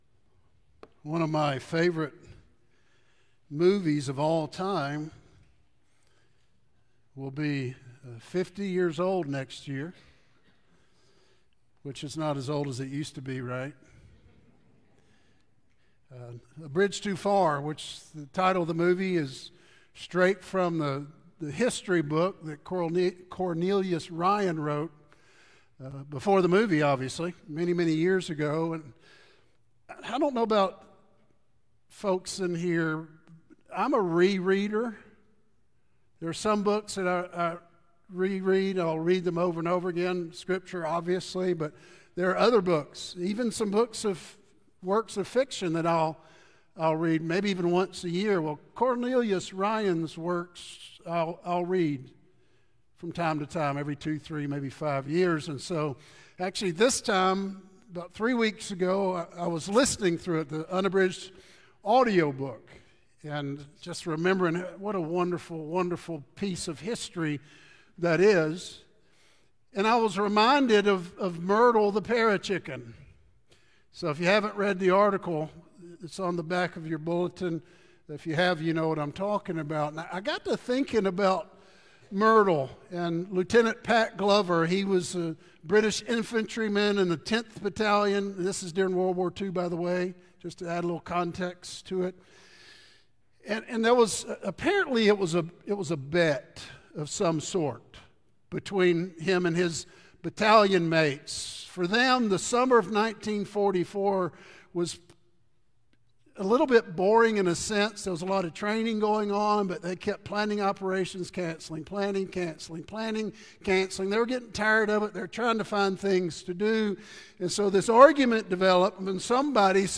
Sermons | West Metro Church of Christ